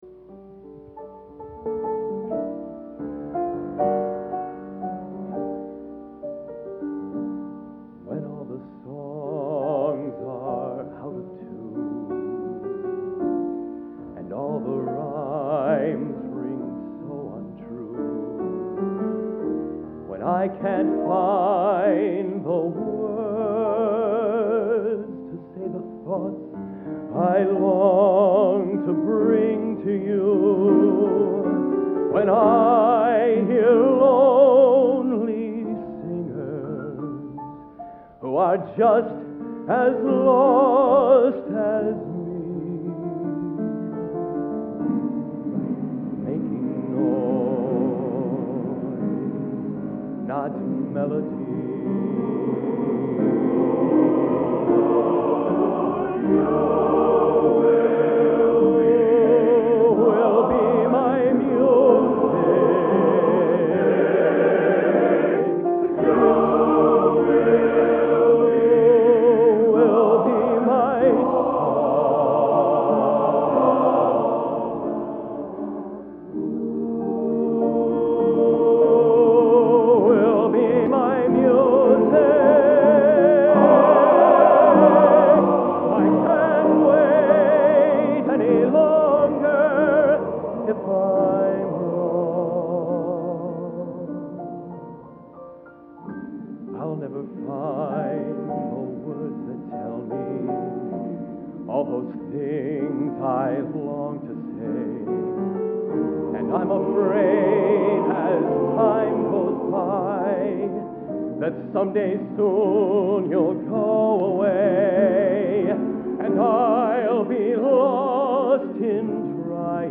Collection: End of Season, 1987
Location: West Lafayette, Indiana
Genre: | Type: Solo